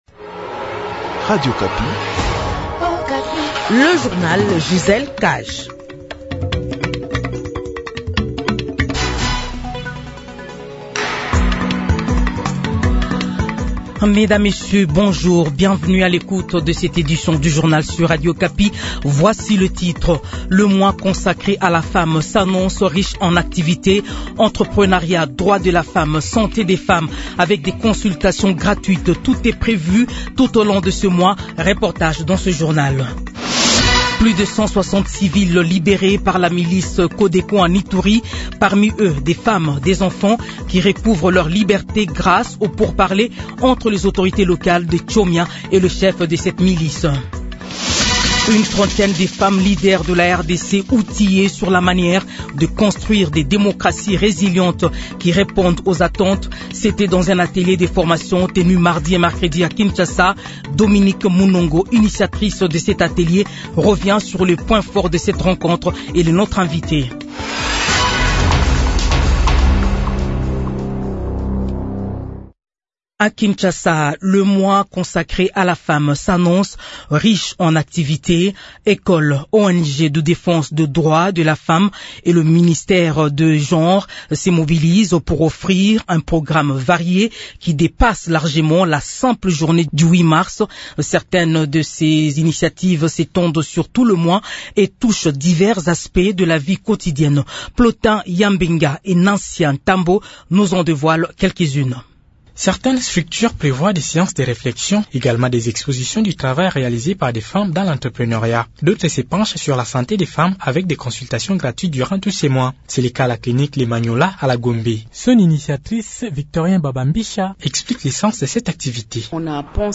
Journal 15heures